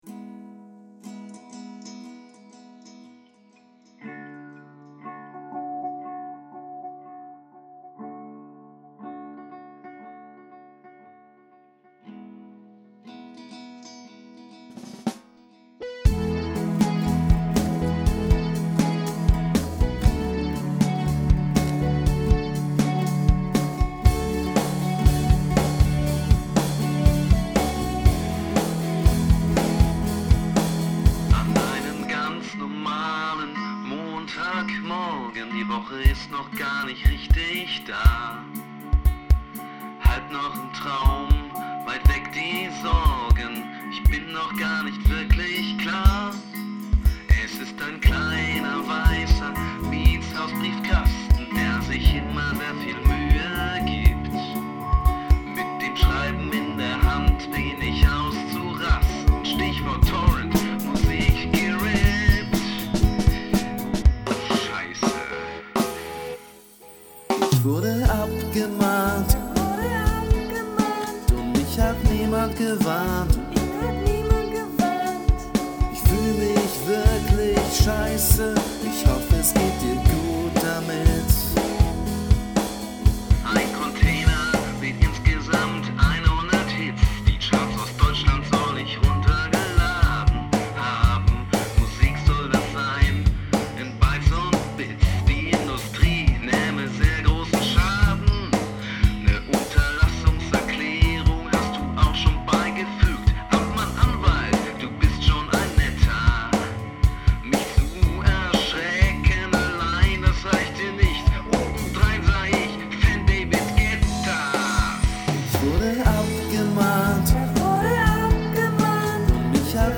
Eine Art Protestsong.